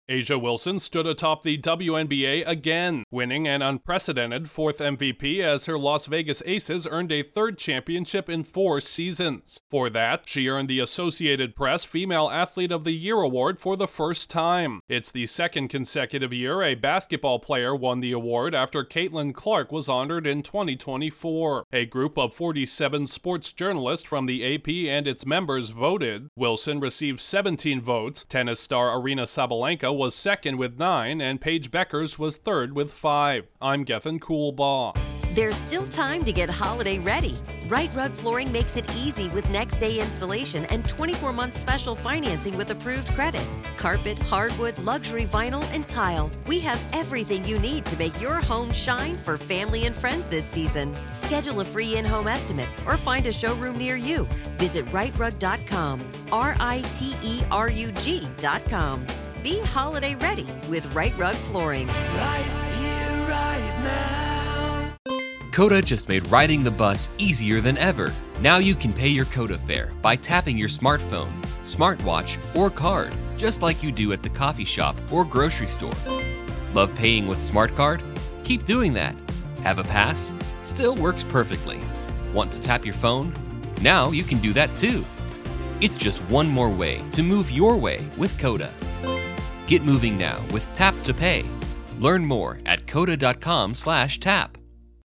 A women’s basketball star is the AP Female Athlete of the Year following her historic fourth WNBA MVP award. Correspondent